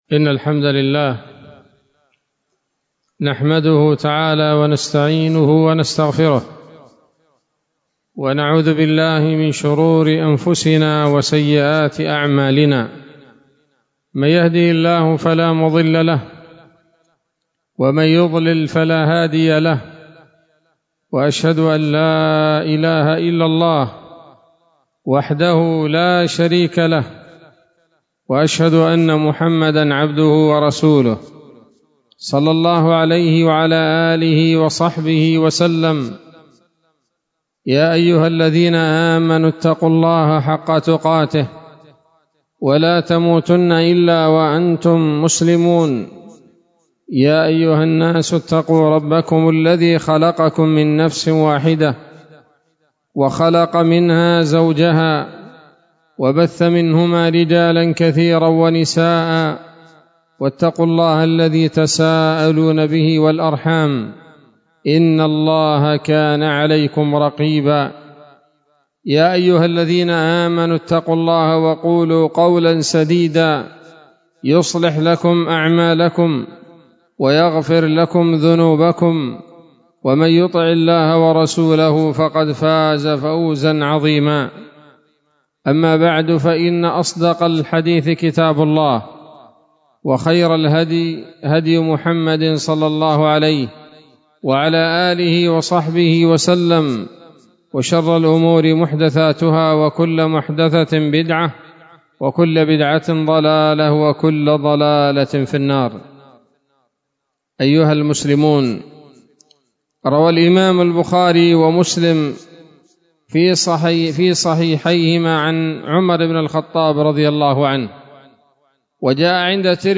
خطبة جمعة بعنوان: (( يوم عرفة )) 09 من شهر ذي الحجة 1443 هـ، دار الحديث السلفية بصلاح الدين